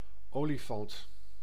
Ääntäminen
France: IPA: [ɛ̃.n‿e.le.fɑ̃]